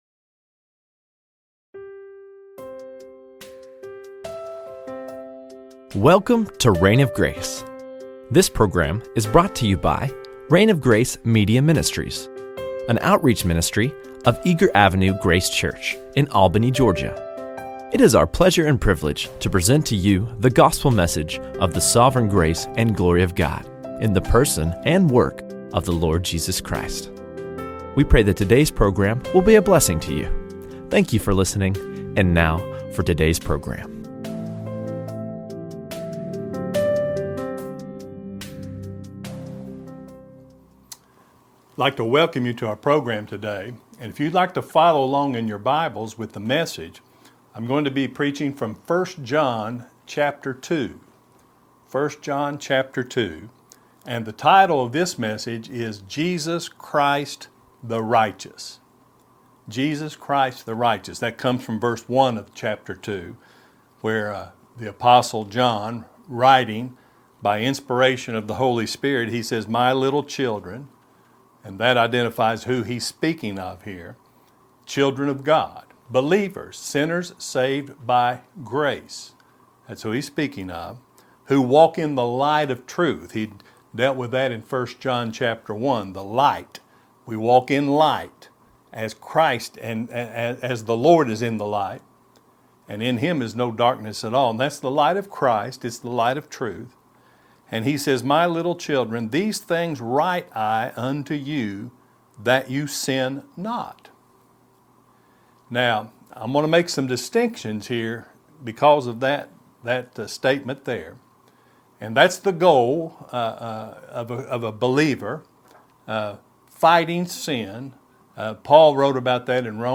Jesus Christ the Righteous | SermonAudio Broadcaster is Live View the Live Stream Share this sermon Disabled by adblocker Copy URL Copied!